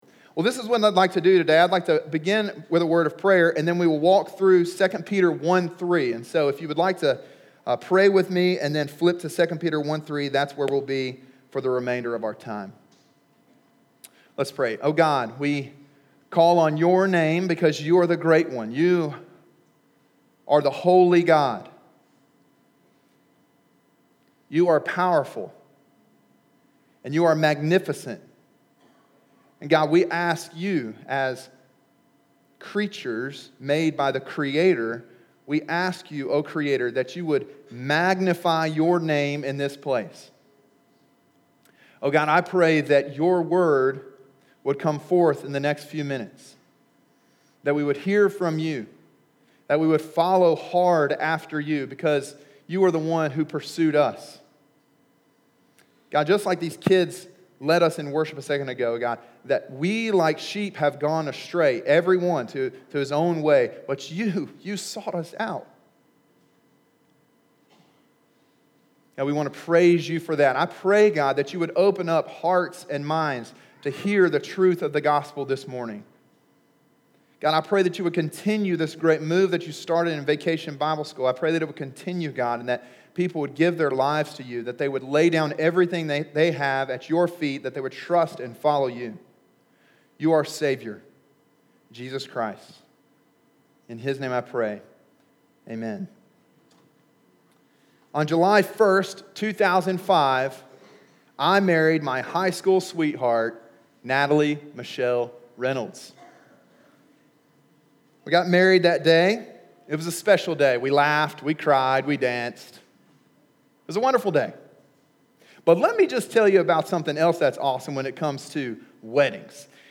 sermon6-10-18.mp3